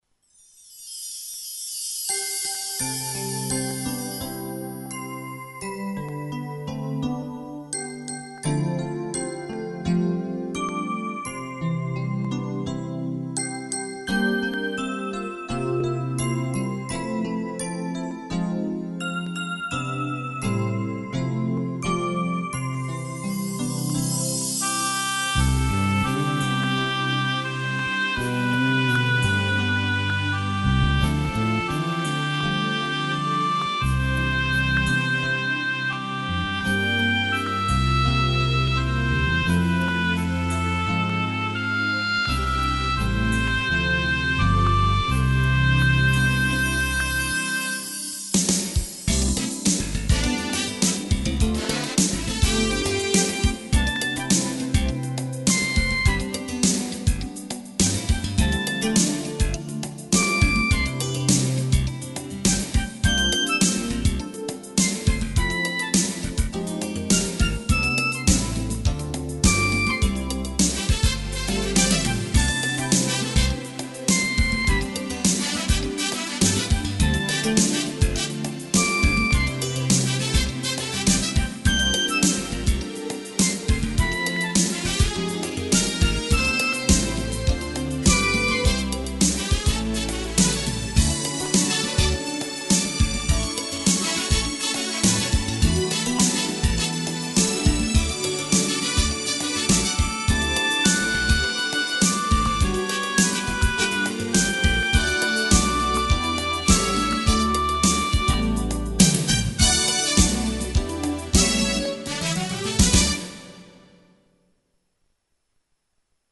Instrumental – Feliz Cumpleaños
Instrumental-Feliz-Cumpleaños.mp3